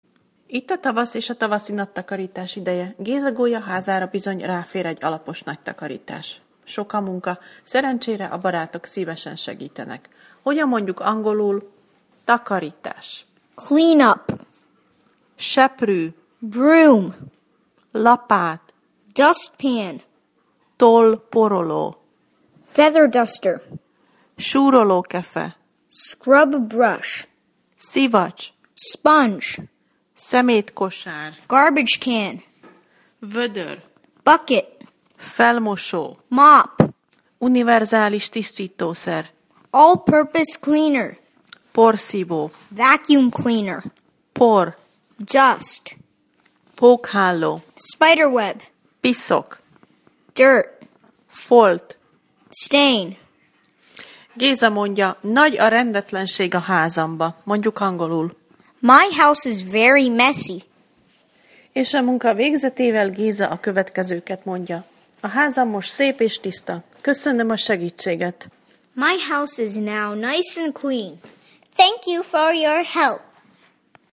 A hangos szótár eléréséhez kattints